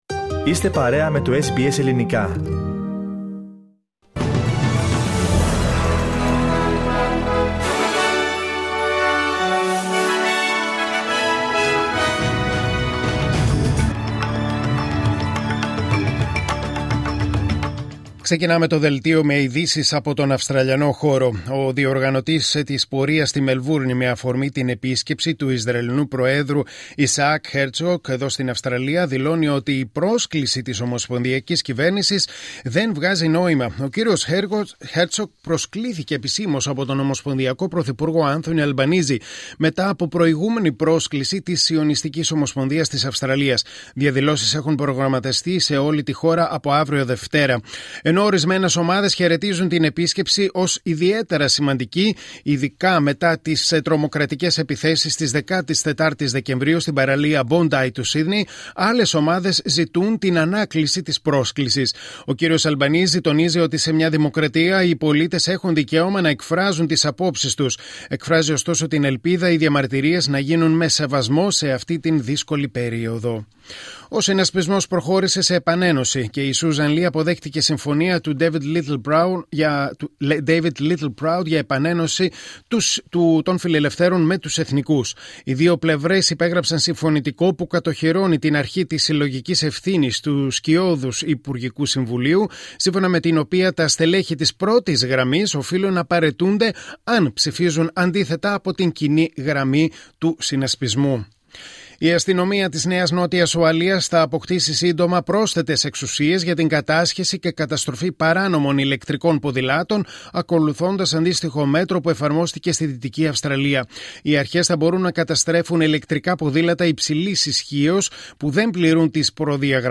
Δελτίο Ειδήσεων Κυριακή 08 Φεβρουαρίου 2026